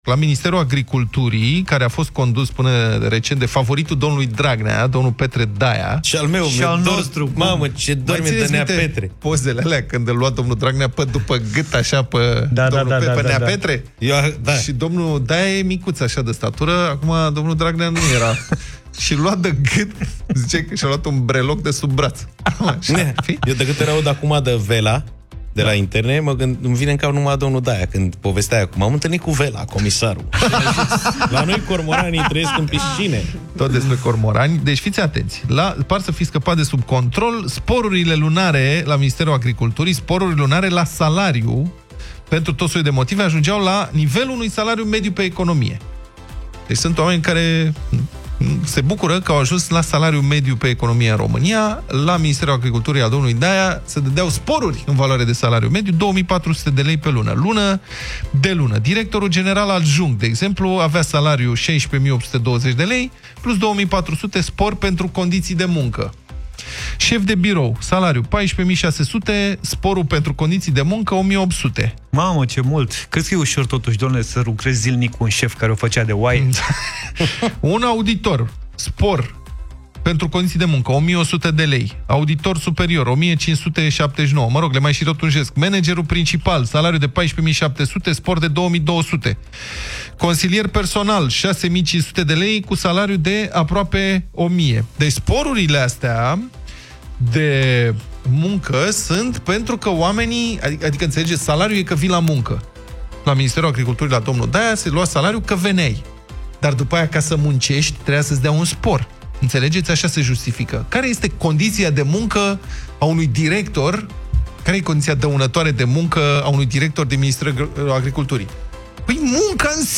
Subiectul a fost discutat de